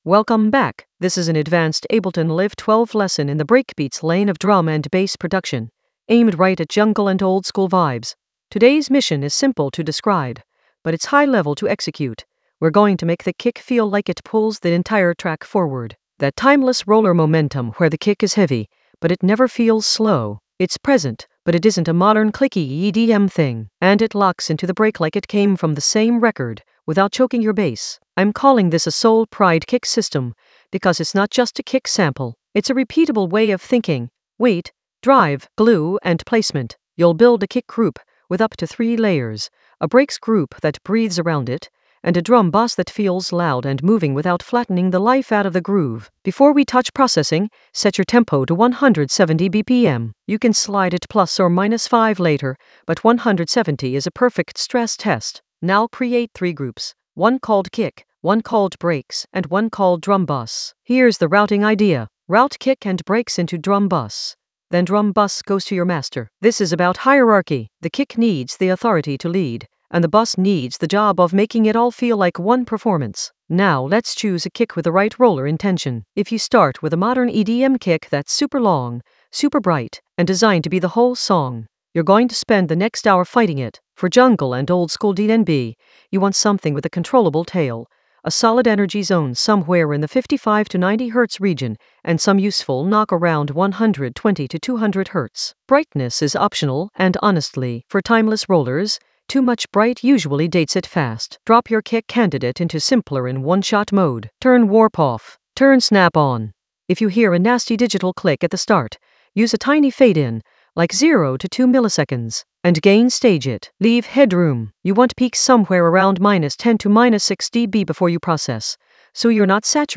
Narrated lesson audio
The voice track includes the tutorial plus extra teacher commentary.
An AI-generated advanced Ableton lesson focused on Soul Pride: kick weight drive for timeless roller momentum in Ableton Live 12 for jungle oldskool DnB vibes in the Breakbeats area of drum and bass production.